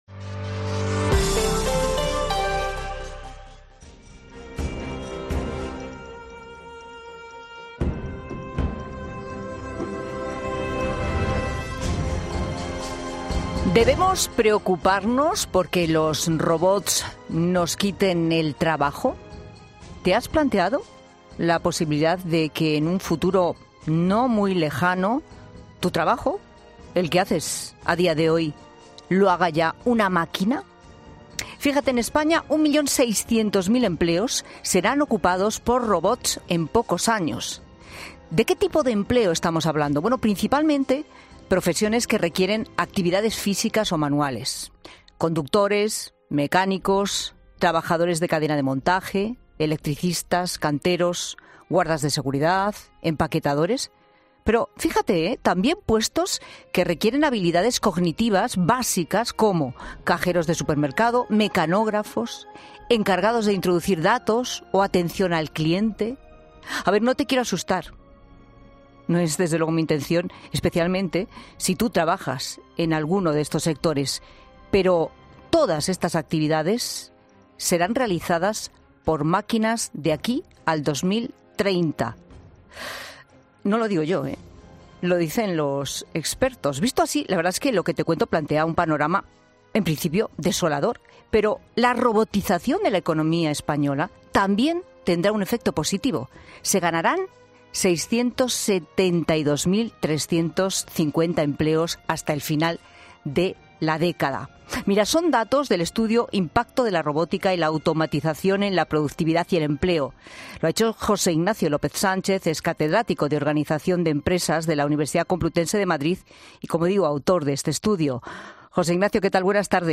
En La Tarde de COPE